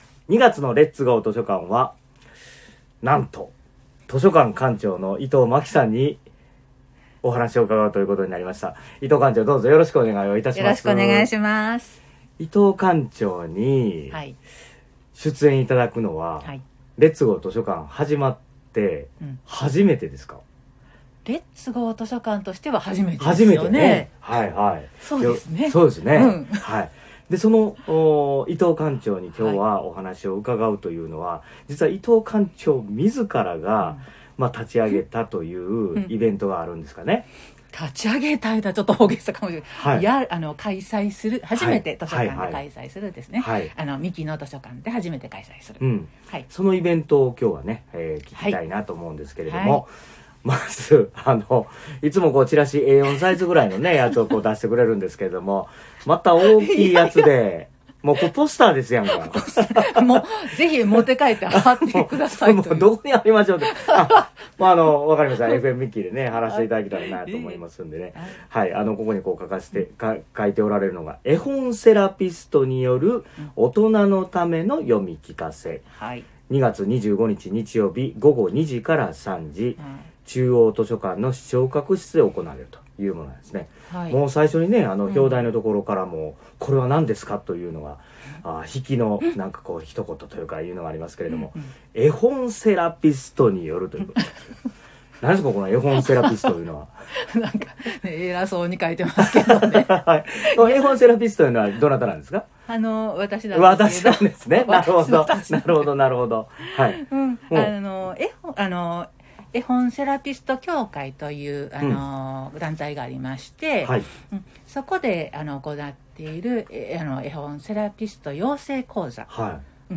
2/25（日）2:00〜3:00 三木市立中央図書館視聴覚室 イベントに関するインタビューはこちら。